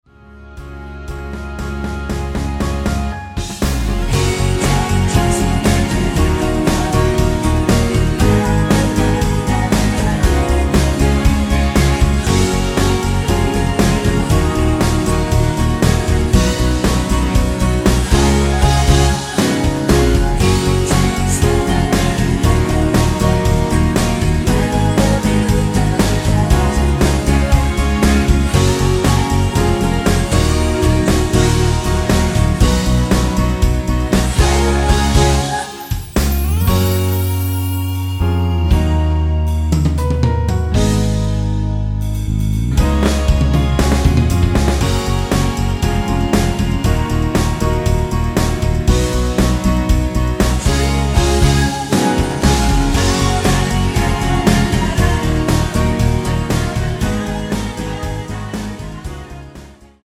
코러스 MR
F#
앞부분30초, 뒷부분30초씩 편집해서 올려 드리고 있습니다.
중간에 음이 끈어지고 다시 나오는 이유는